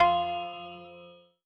SFX_Menu_Confirmation_06.wav